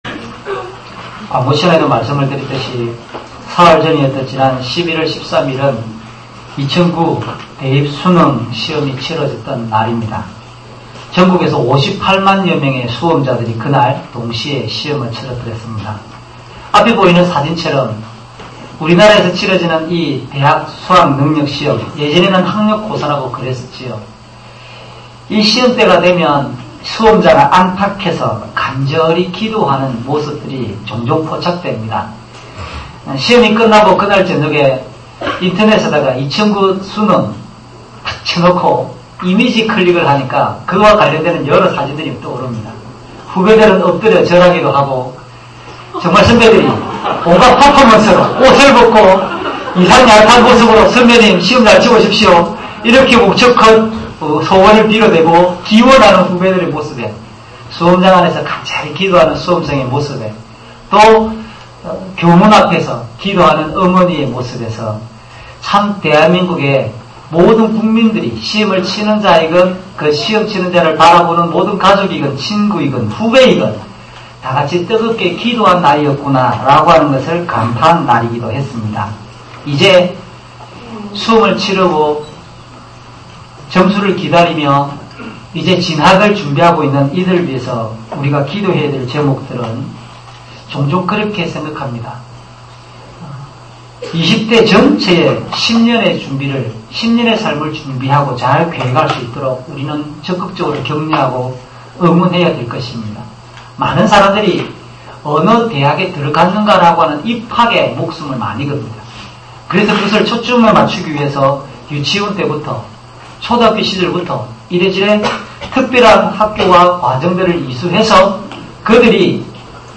주일설교 - 08년 11월 16일 "세상과 다른 복을 누려야 합니다."